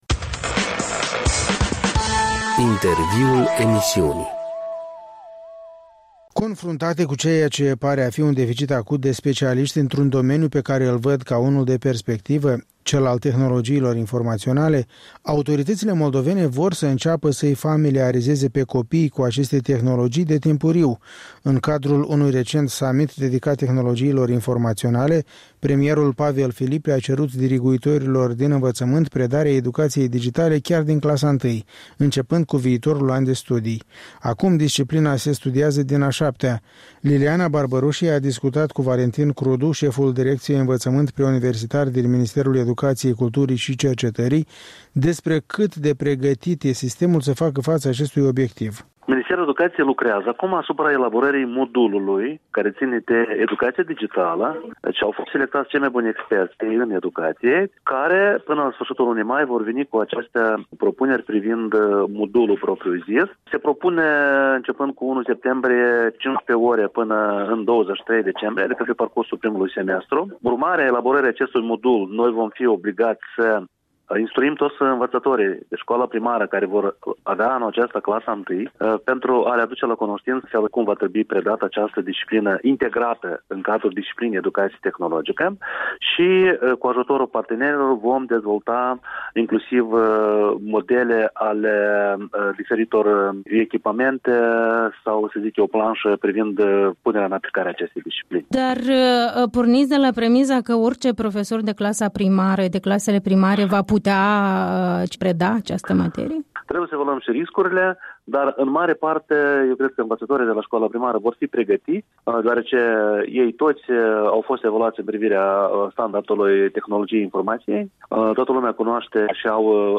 Un interviu despre planurile de educație „digitală” cu șeful Direcției învățământ preuniversitar la Ministerul Educației, Culturii şi Cercetării.